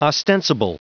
Prononciation du mot ostensible en anglais (fichier audio)
Prononciation du mot : ostensible